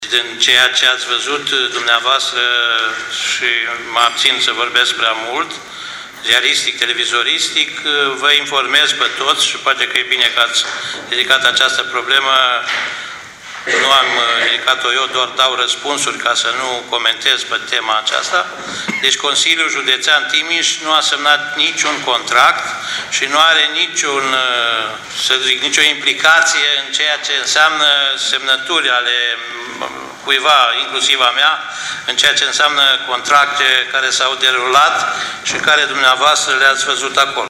AUDIO / Un consilier județean a cerut, în plen, demisia președintelui CJT